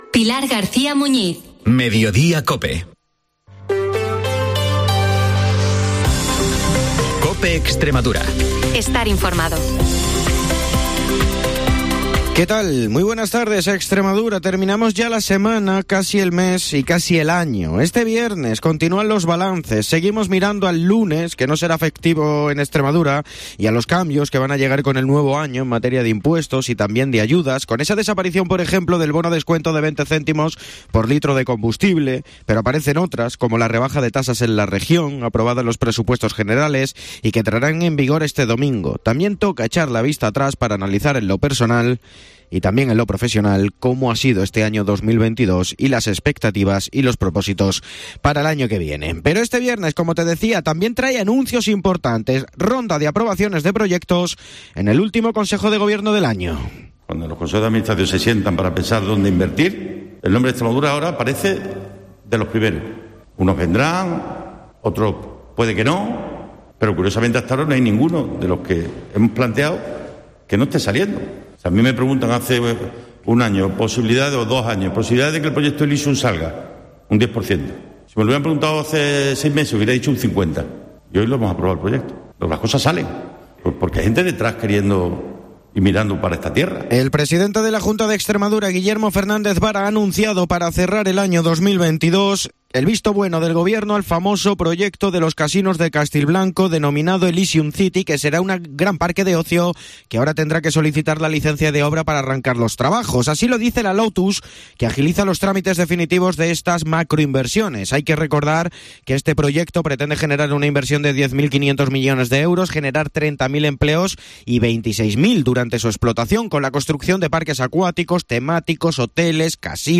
te cuenta la última hora de la región de lunes a viernes a las 14:48 horas en...